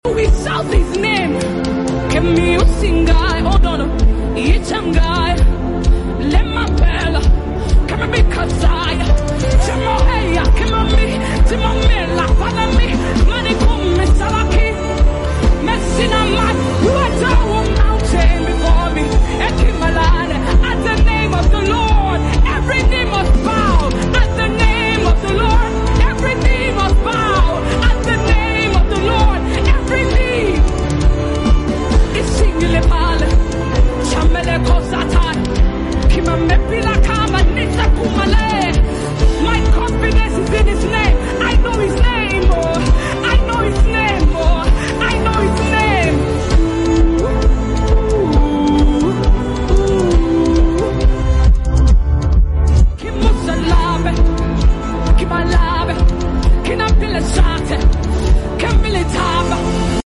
powerful worship